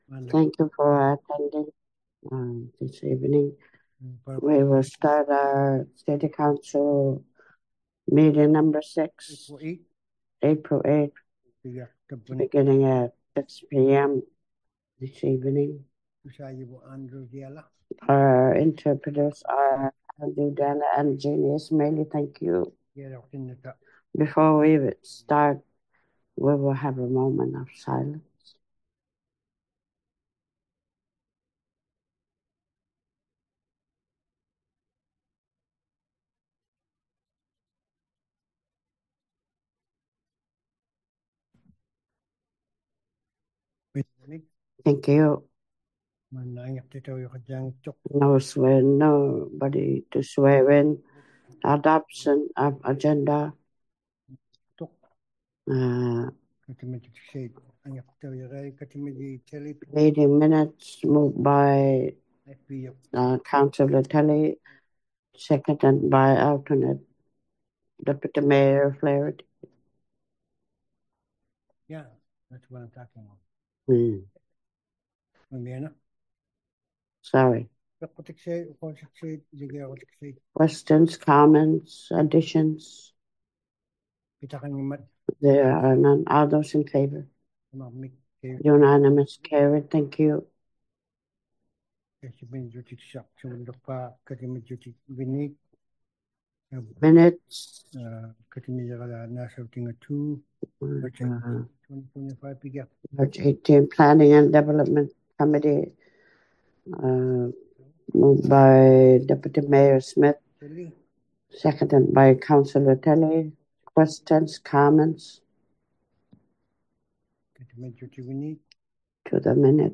City Council Meeting #06 | City of Iqaluit